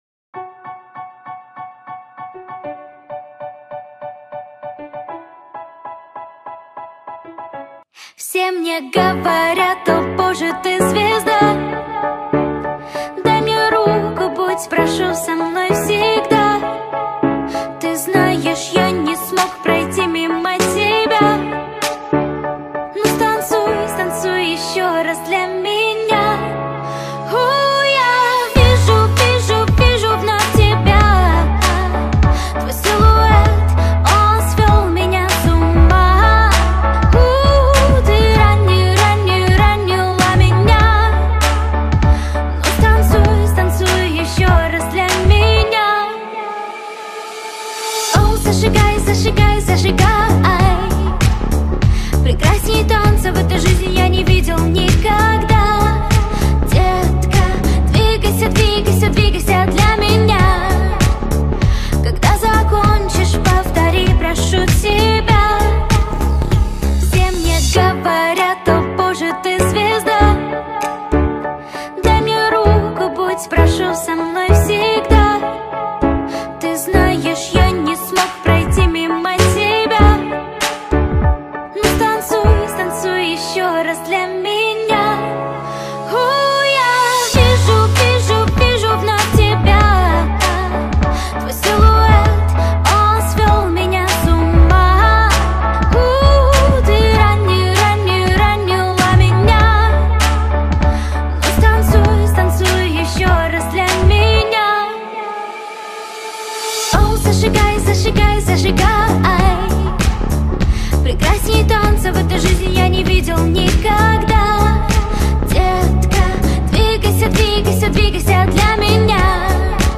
Стиль: Pop / Dance